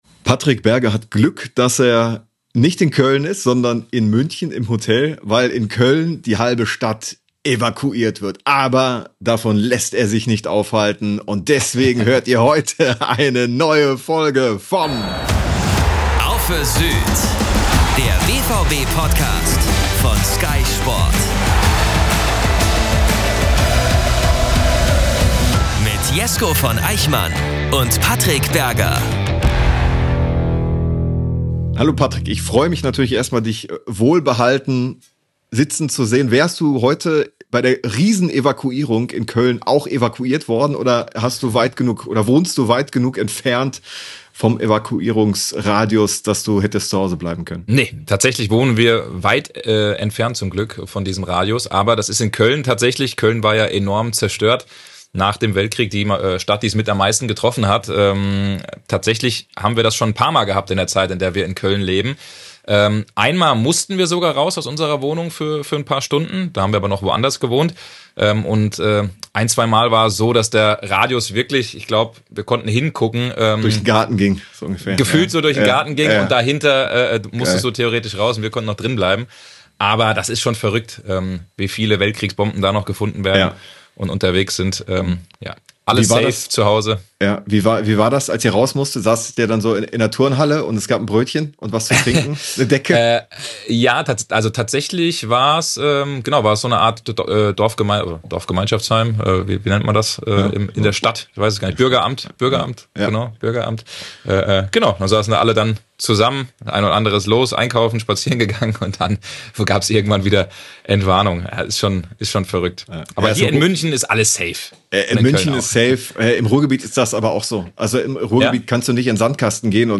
Eine Stunde lang gibt es für euch wieder alle heißen Themen rund um den BVB. Der bevorstehende Transfer von Jobe Bellingham wird zwischen den beiden Reportern kontrovers diskutiert – bringt er die Borussia wirklich weiter oder ist er nur ein Abklatsch seines zwei Jahre älteren Bruders Jude?